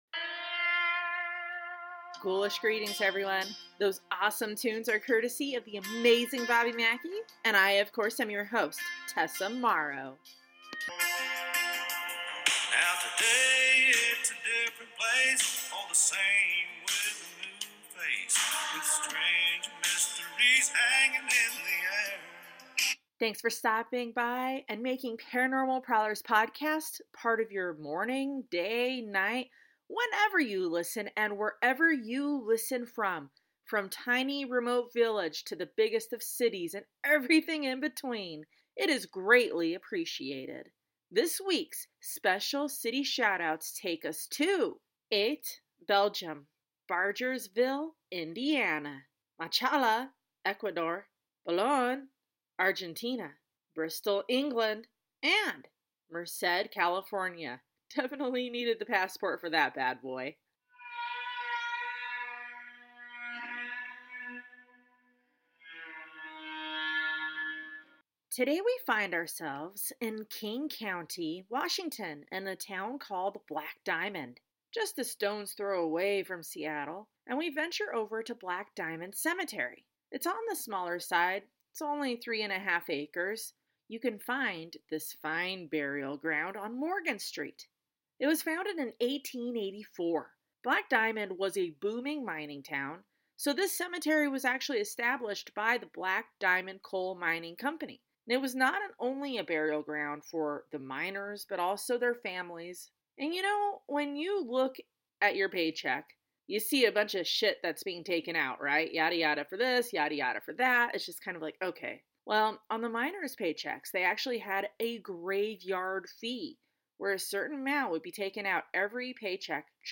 Horse neigh at Del Norte, Colorado